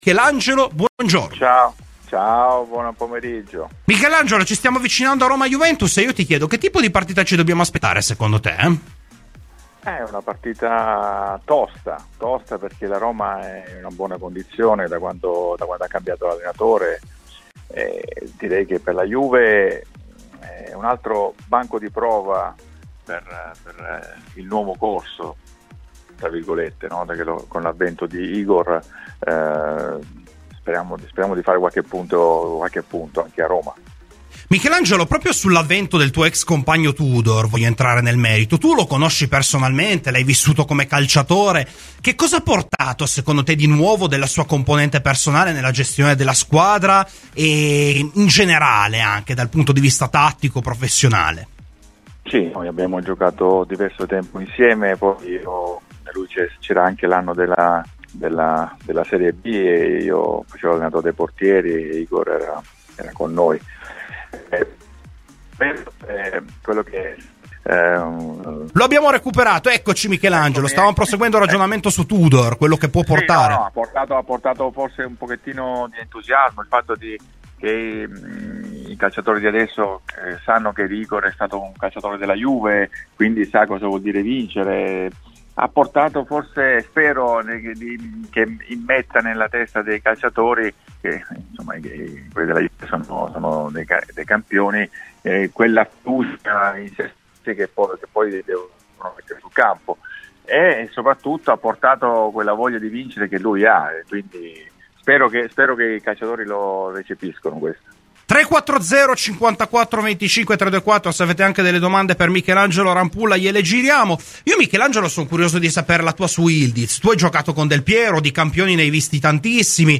Ospite di "Domenica Sport" su Radio Bianconera, l'ex portiere della Juventus Michelangelo Rampulla ha parlato di svariati temi legati ai bianconeri, a cominciare dal portiere, con Di Gregorio che ormai è il titolare designato: "Sono convinto che farà molto molto bene ed io sposo la scelta della società, che puntando sull'ex Monza ha voluto assicurarsi anche in ottica futura, cosa che per forza di cose non era possibile fare con Szczesny, un fuoriclasse ma in là con gli anni.